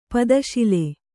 ♪ pada śile